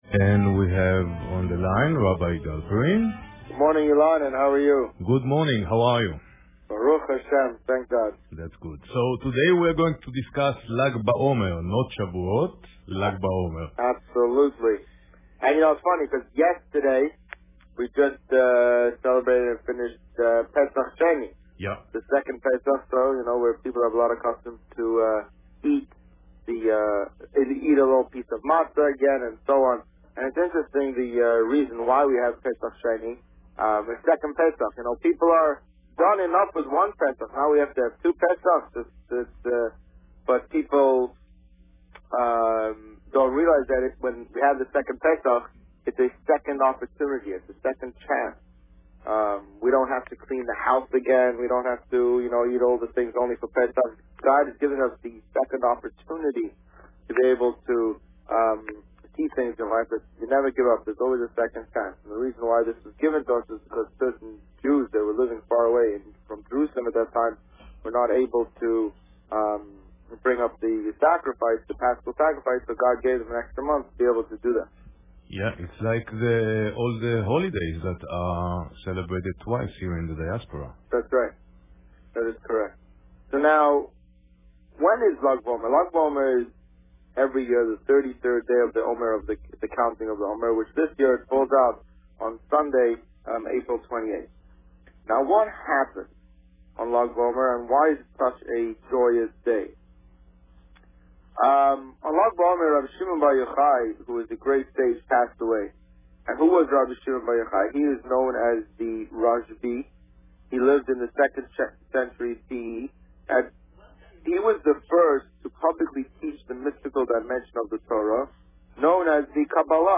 The Rabbi on Radio
The meaning of Lag B'Omer Published: 25 April 2013 | Written by Administrator On April 25, 2013, the Rabbi spoke about the origins, customs and significance of Lag B'Omer. Listen to the interview here .